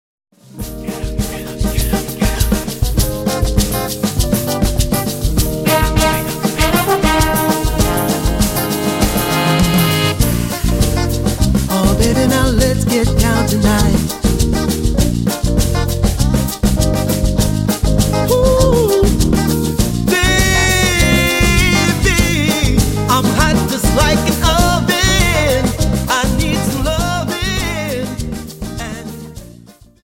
Dance: Samba 50